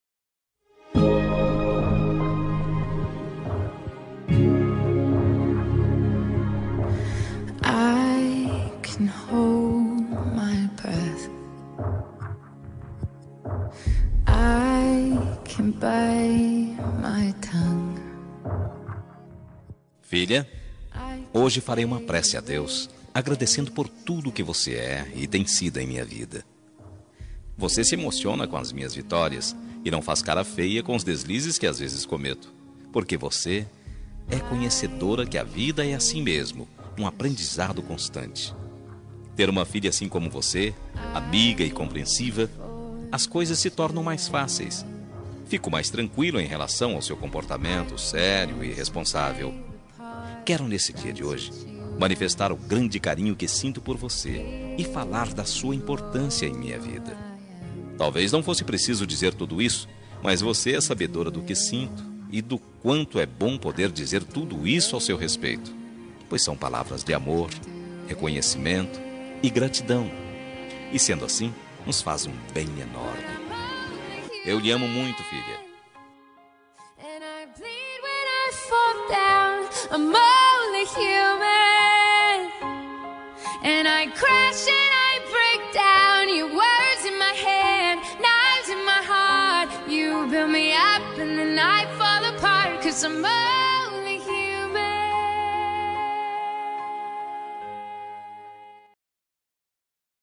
Homenagem para Filha – Voz Masculina – Cód: 8139